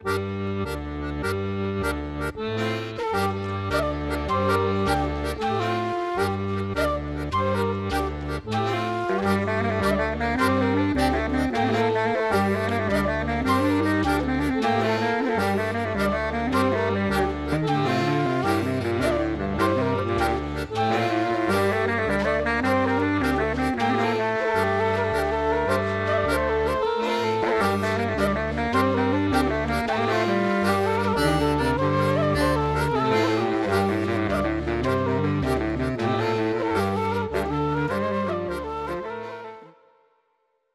Gavotte